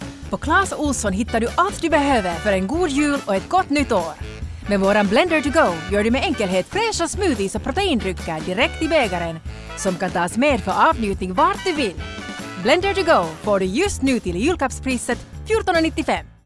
Finnish, Scandinavian, Female, Home Studio, Teens-40s
Home Studio Read